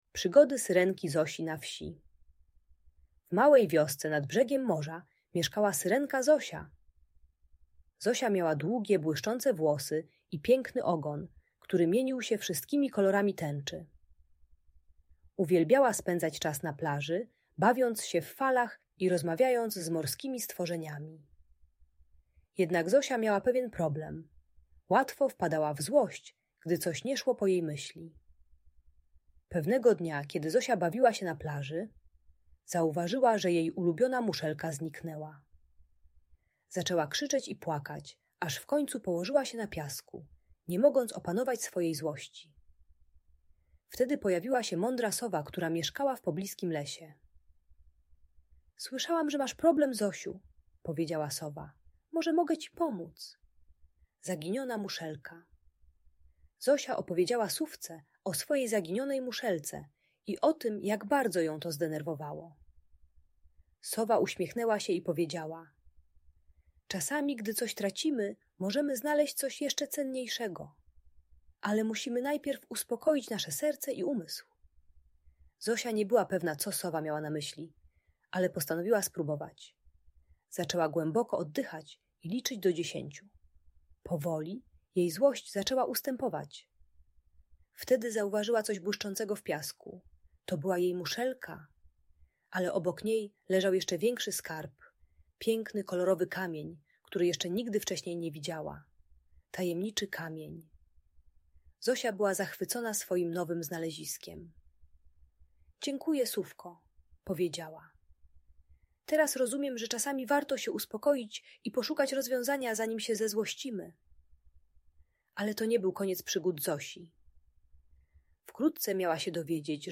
Przygody Syrenki Zosi - Bajkowa Historia o Spokoju - Audiobajka